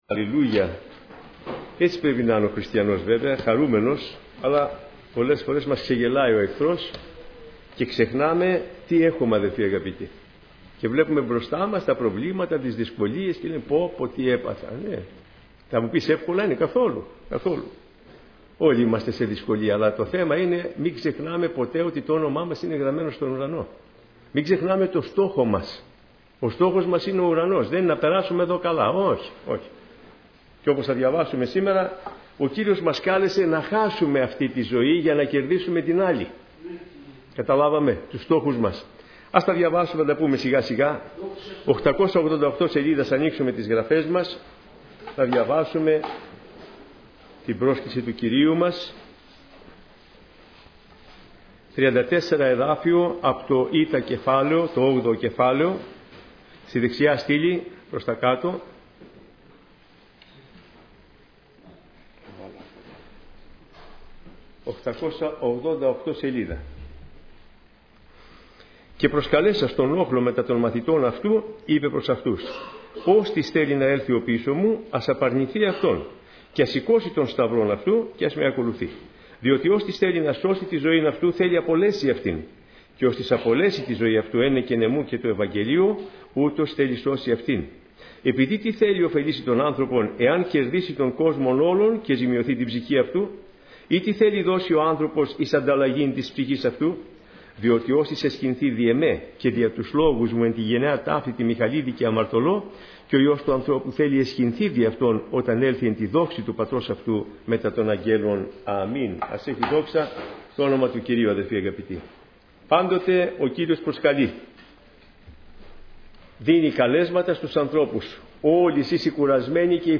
Κηρύγματα